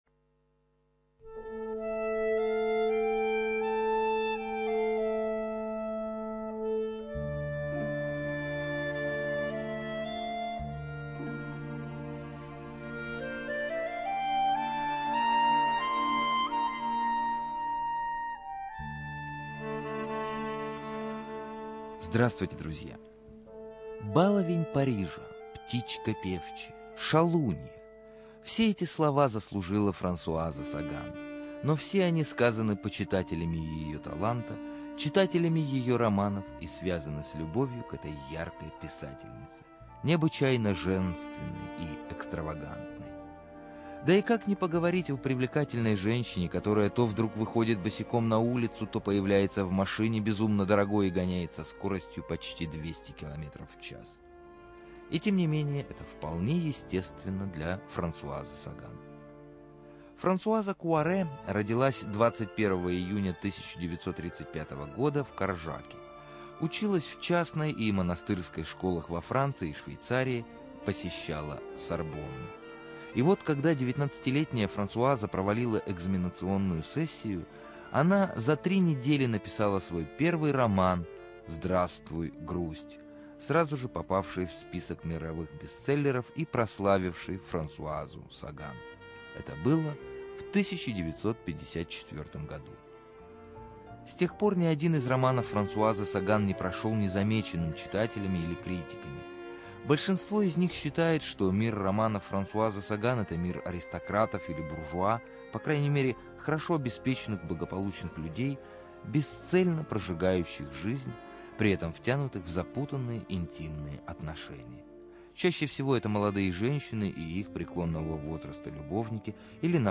Студия звукозаписиРадио 4